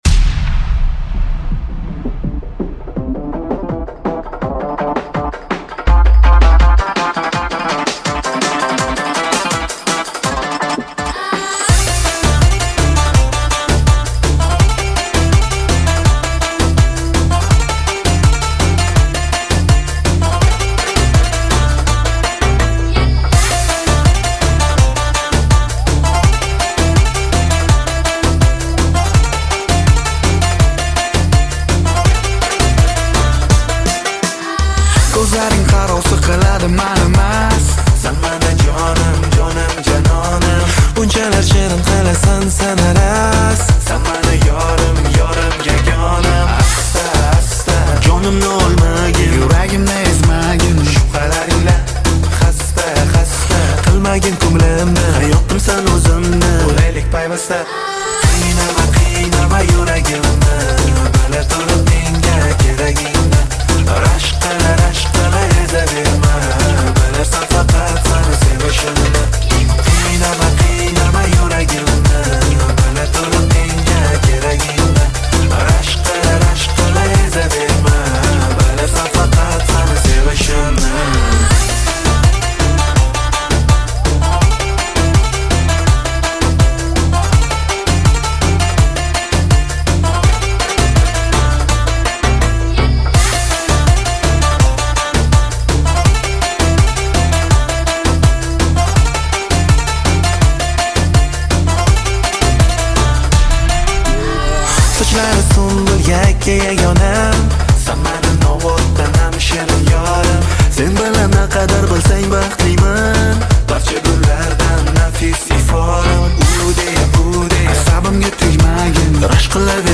• Жанр: New Uzb / Узбекские песни